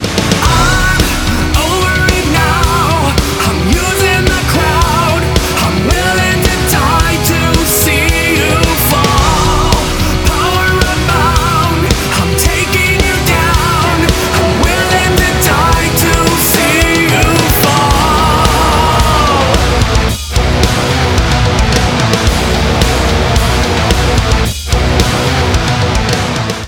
• Качество: 192, Stereo
рок